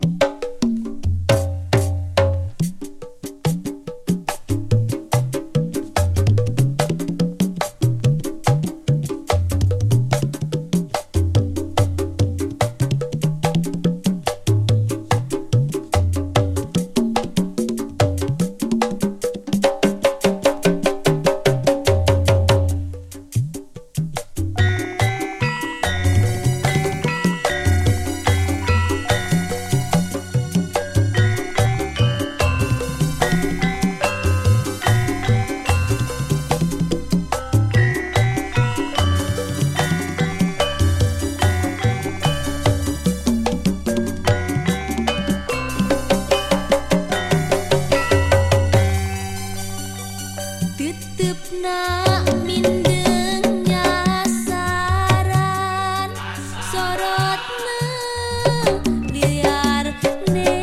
インドネシアン・ポップスの歌姫